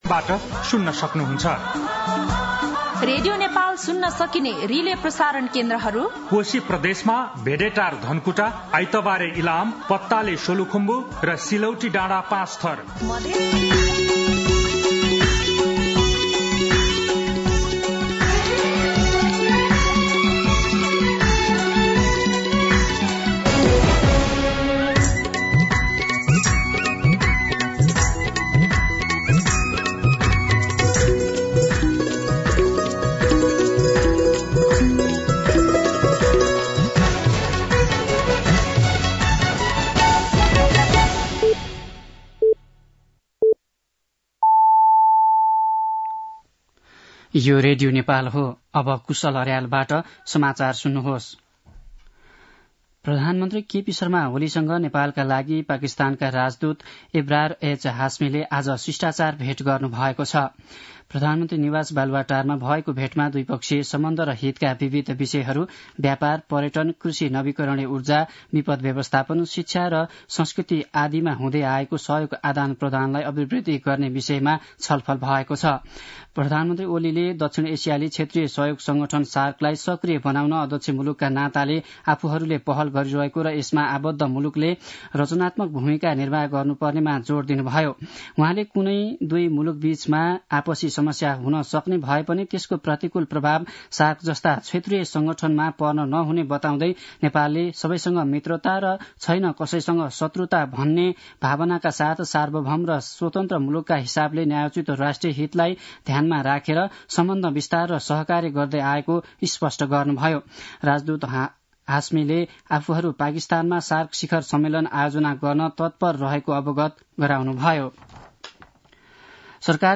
दिउँसो ४ बजेको नेपाली समाचार : १० पुष , २०८१
4pm-nepali-news-1-2.mp3